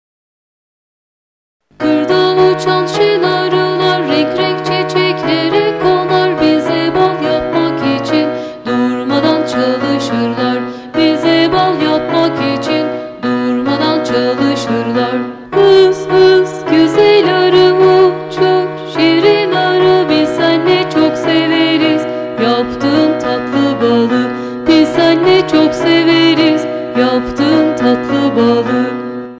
Kategori Çocuk Şarkıları